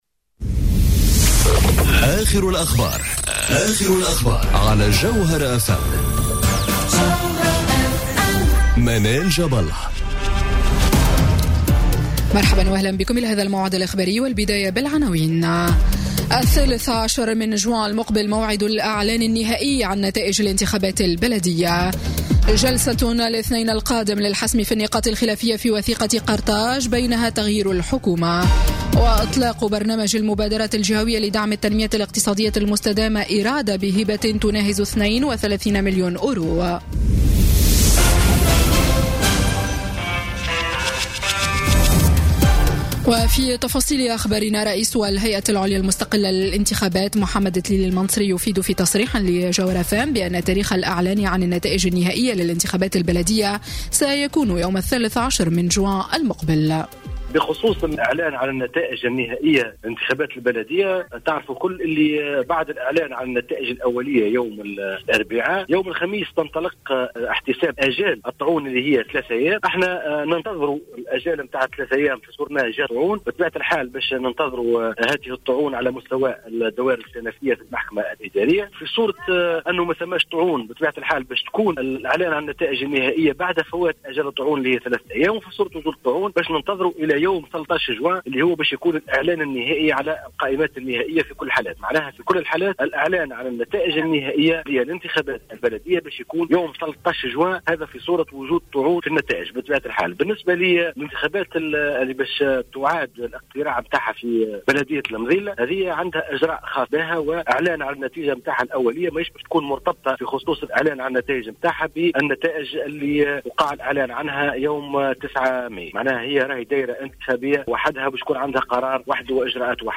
نشرة أخبار السابعة مساءً ليوم الجمعة 11 ماي 2018